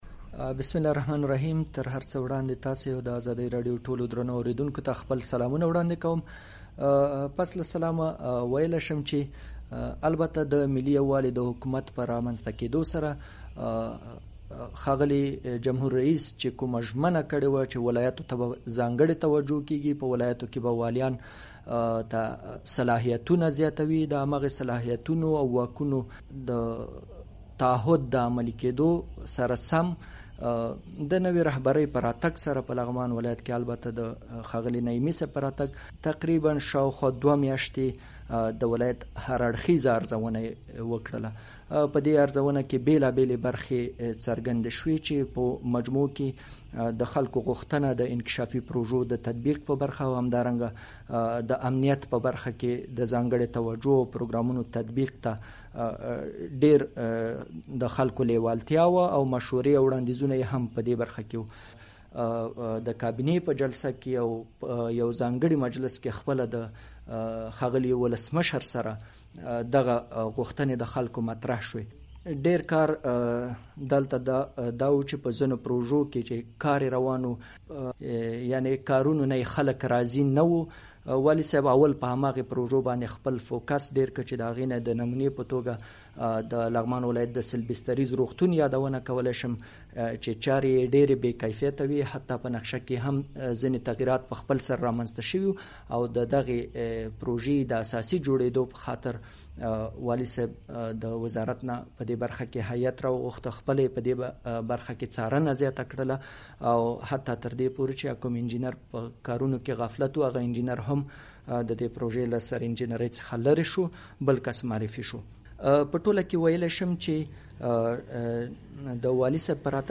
ځانګړې مرکه: